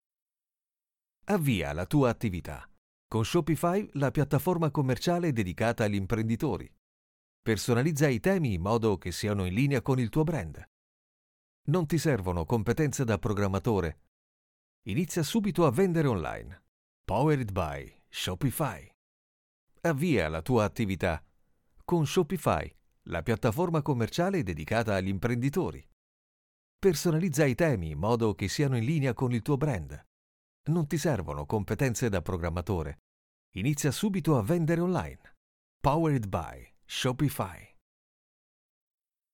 Naturelle, Polyvalente, Fiable, Mature, Douce
E-learning